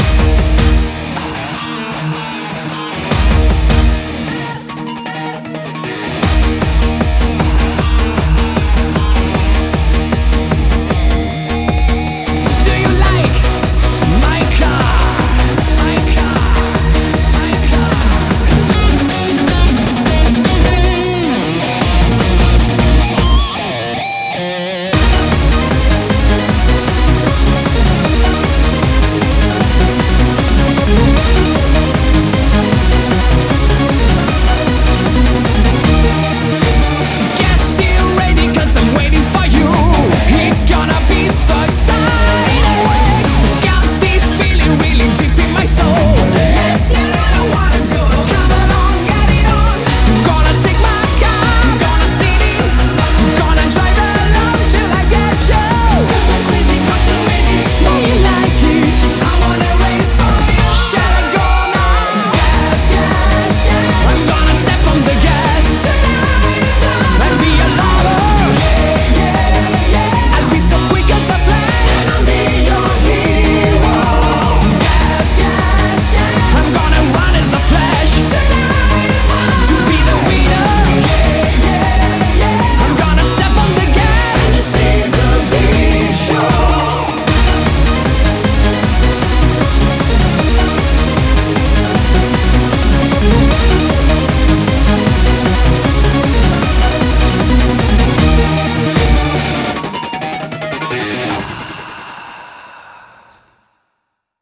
BPM154
Audio QualityPerfect (High Quality)
vinyl edition